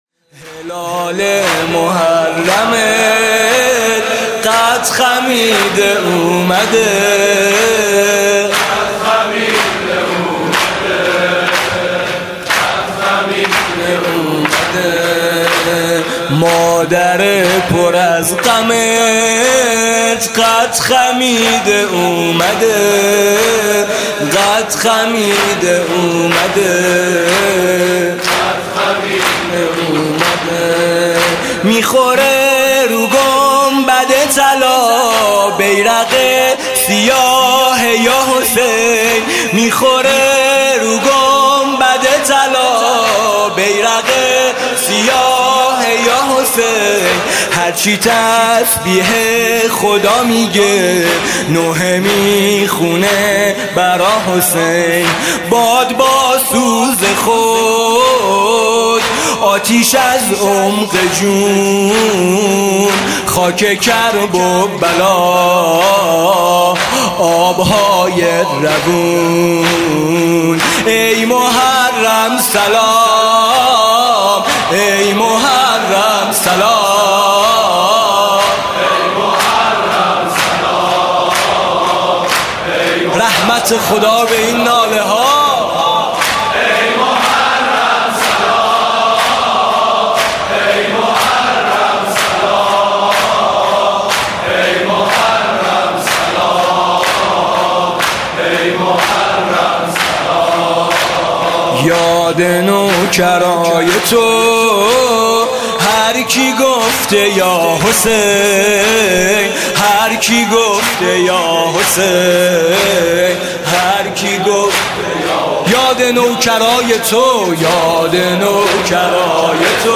«محرم 1396» (شب اول) زمینه: حلال محرم قد خمیده اومد